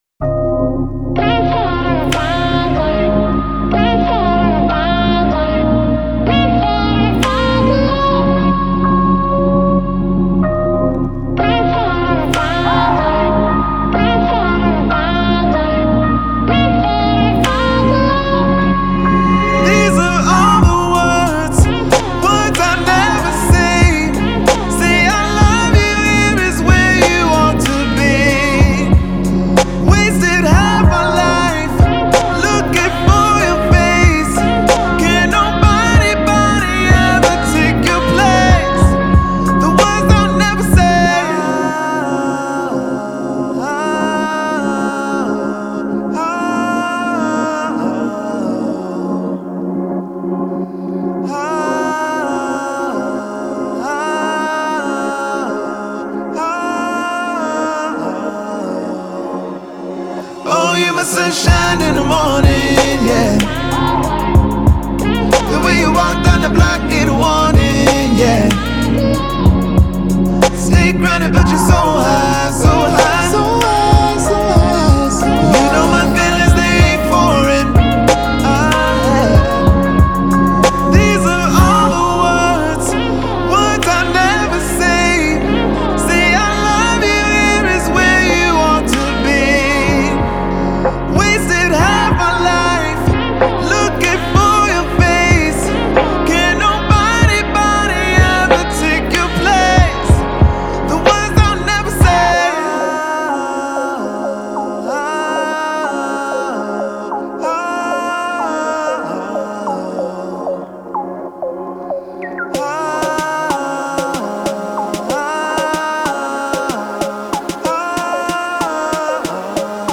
Genre: Soundtrack.